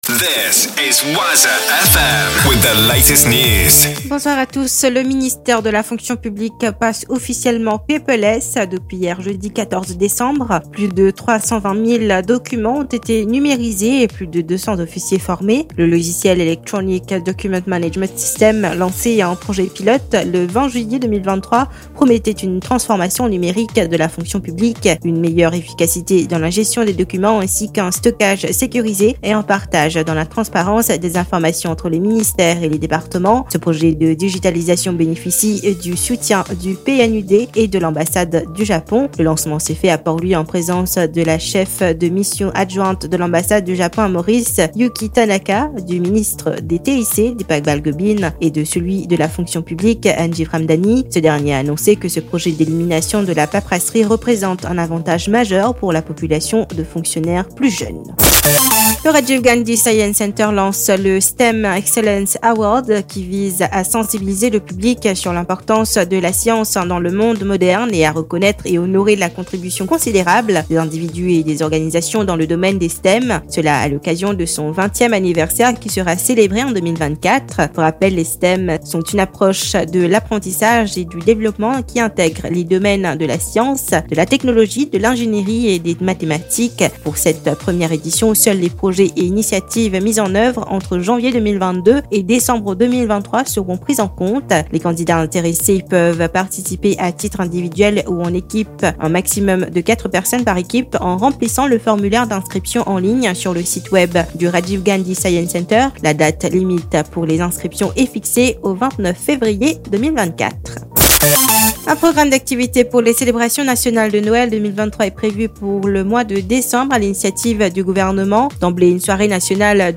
NEWS 20H - 15.12.23